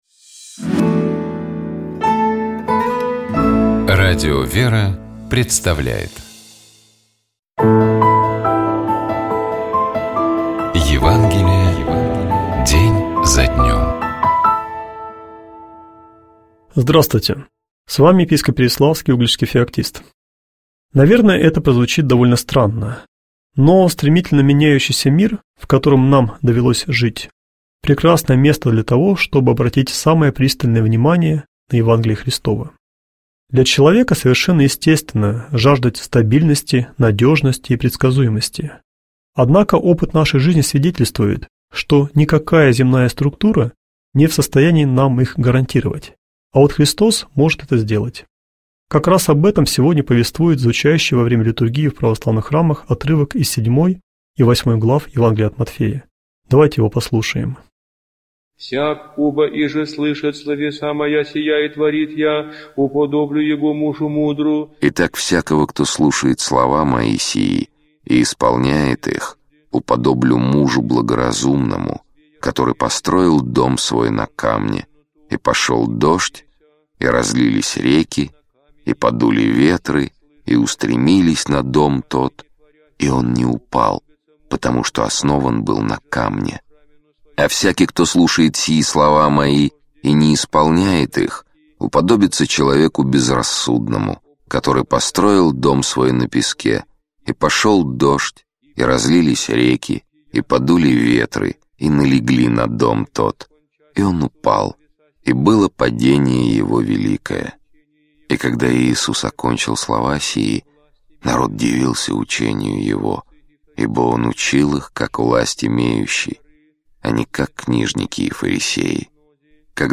Читает и комментирует
епископ Переславский и Угличский Феоктист